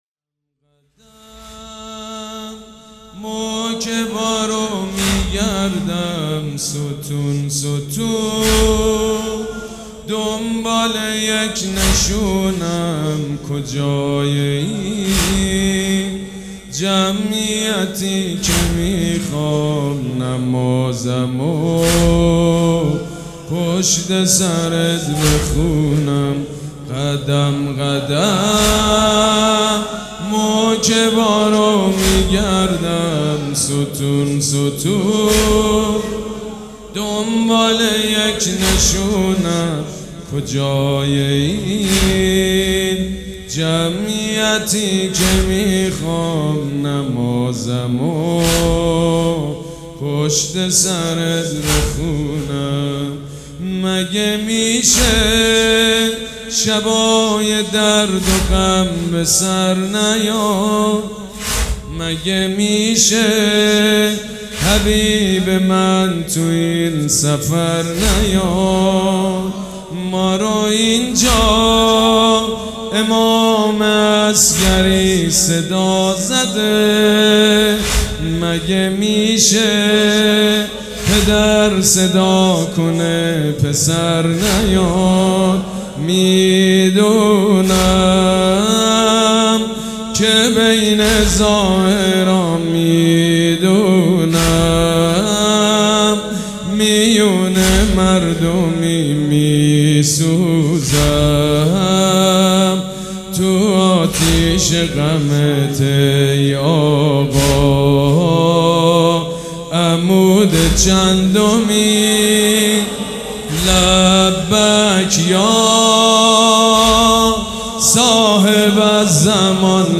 مداح
حاج سید مجید بنی فاطمه
مراسم عزاداری شب دوم